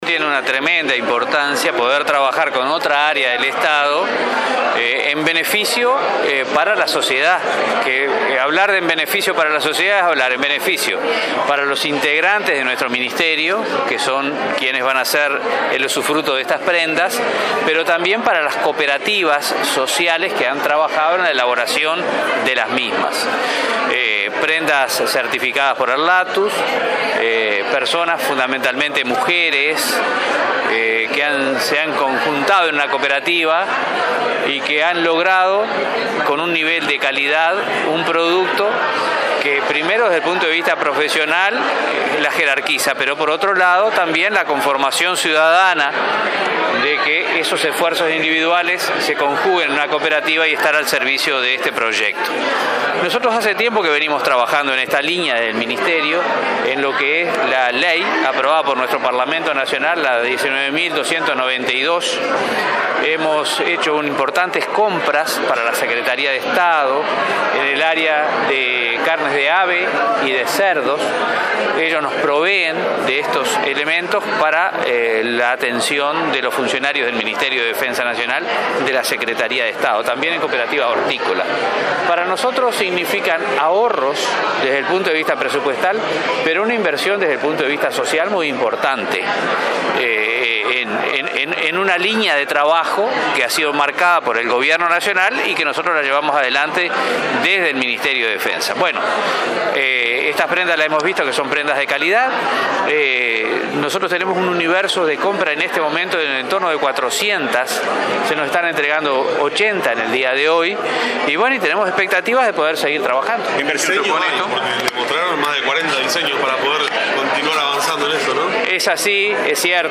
“Es un trabajo interinstitucional en beneficio del personal militar que hará usufrutuo de prendas y para las cooperativas sociales que las elaboraron”, expresó el ministro de Defensa, Jorge Menéndez, en la entrega de 20 uniformes confeccionados por emprendedoras del programa Negocios Inclusivos del Mides.